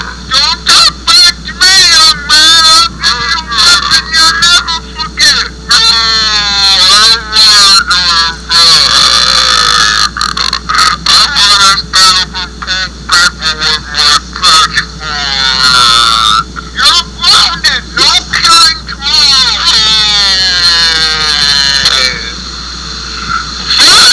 Original dung radio sound bytes! listen to our experimental "satan" trilogy!